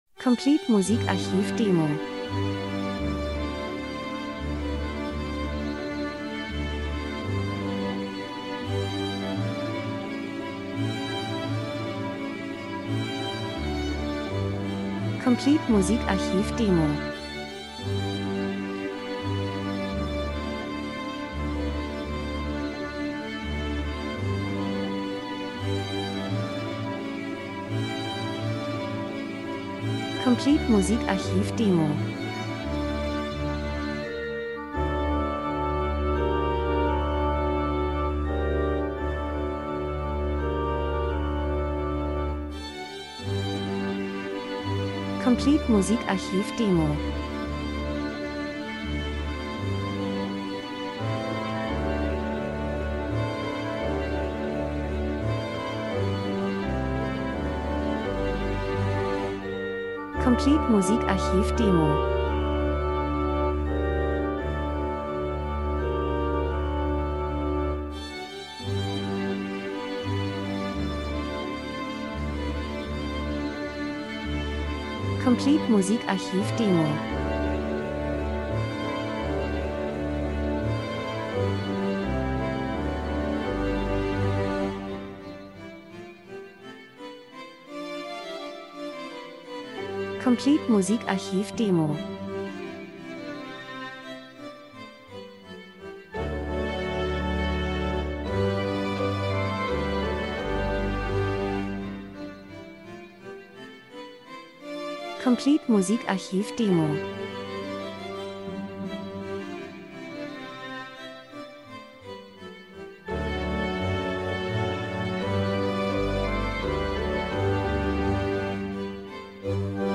Gemafreie Klassik Orchesterbearbeitung